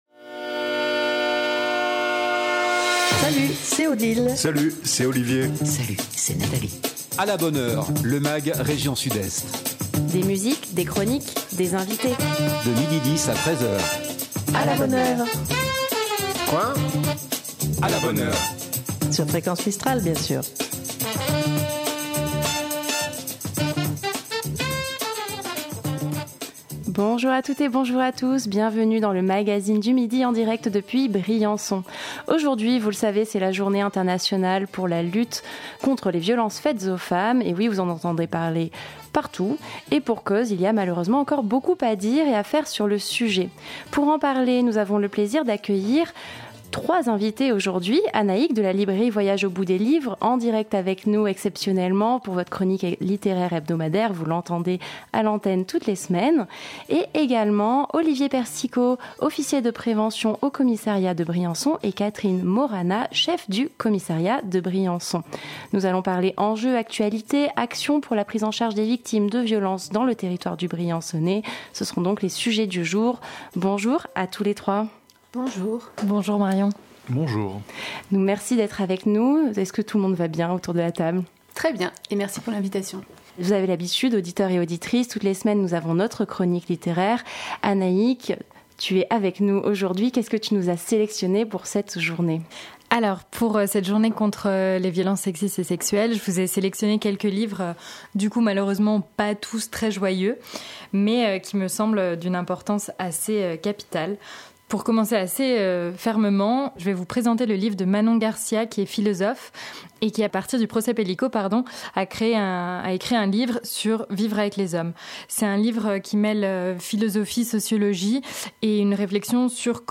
un Mag rien que pour vous, des invité.e.s en direct